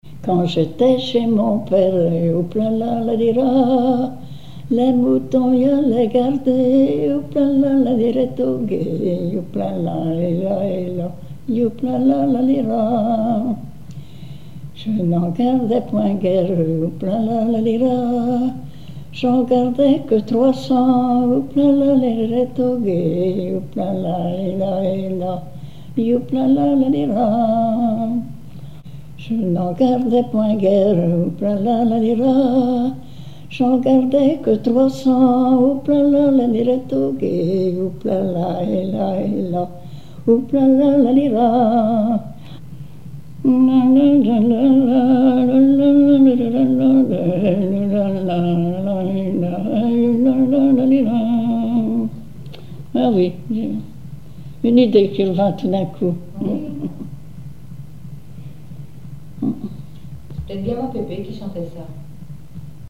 Langue Patois local
Genre laisse
Chansons et témoignages
Pièce musicale inédite